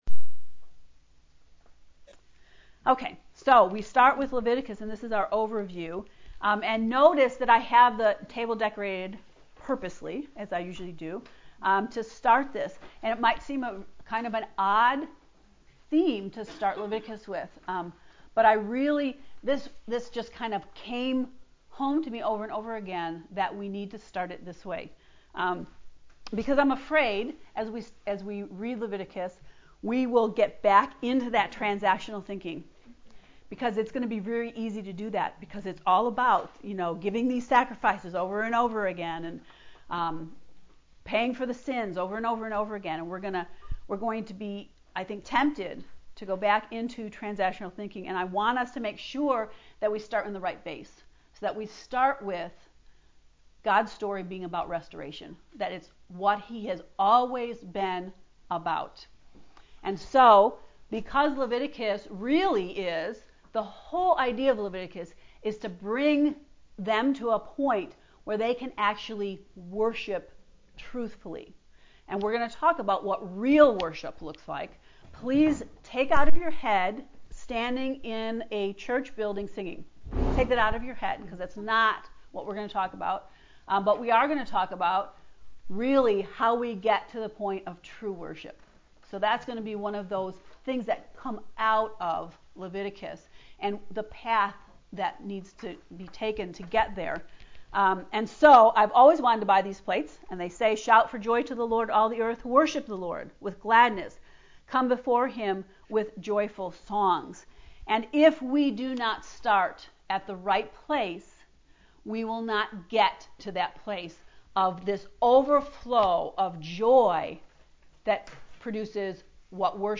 levlecture0.mp3